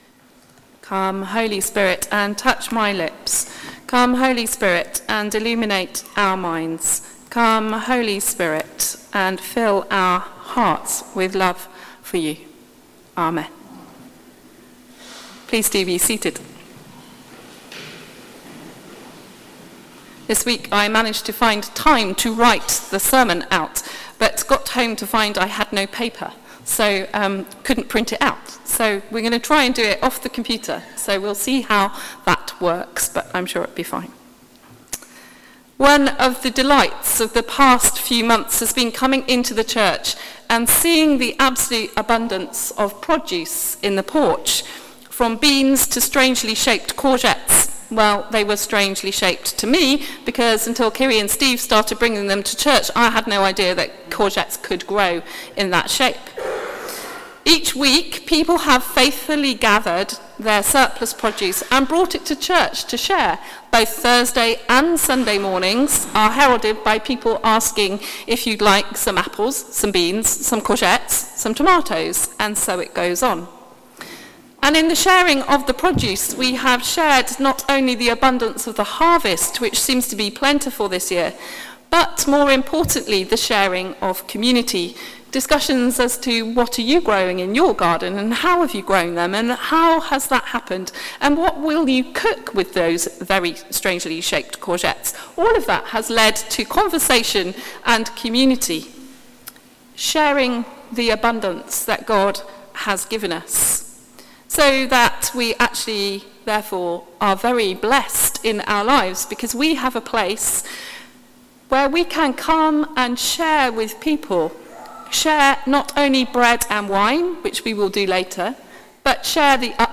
Sermon: Who do you serve?